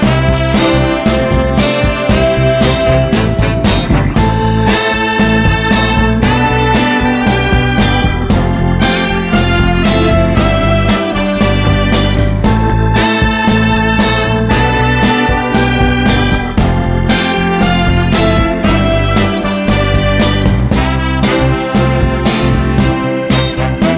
no Backing Vocals Soul / Motown 3:02 Buy £1.50